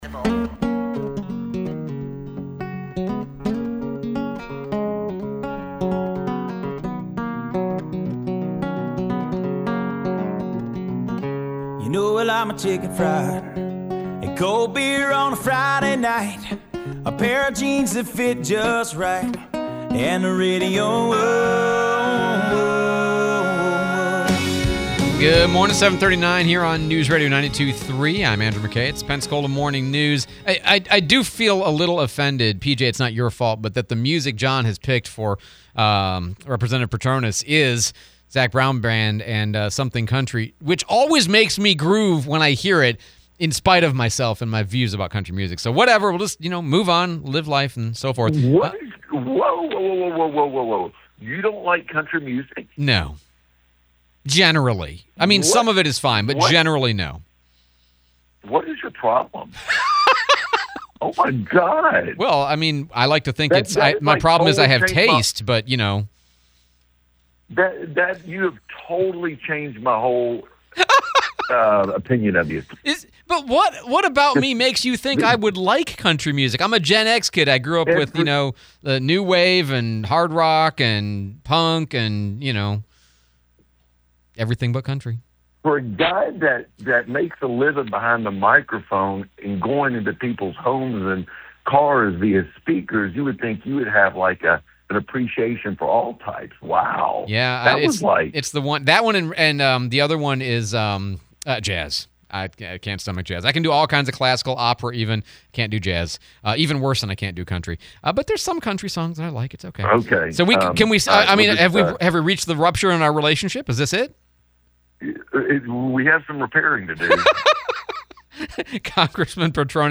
01/30/26 Congressman Patronis interview